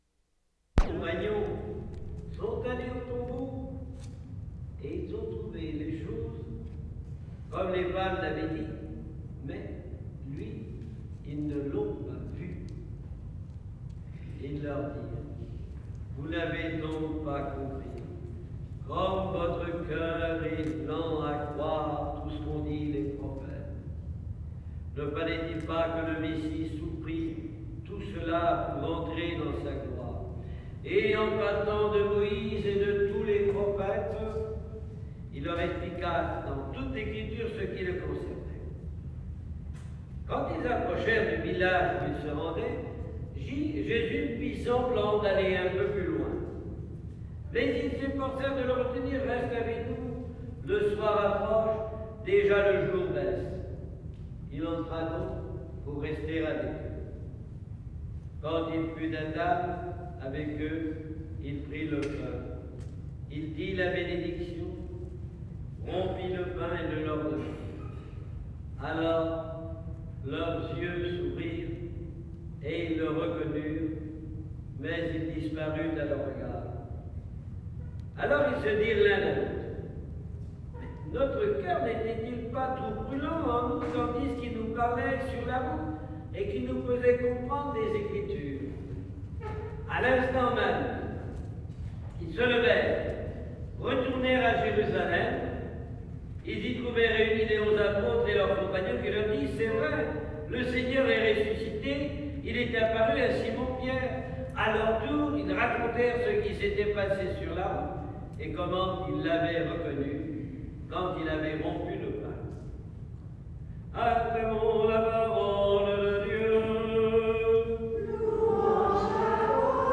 CATHOLIC MASS
" " priest speaking
3. (note 3-6, low rumble from heating system) Priest speaking; the two priests are miked, the amplification is hardly noticeable.
Church quite small, hardly any reverberation.
5. Priest's voice animated and expressive as he tells the story of sermon on the mount(?).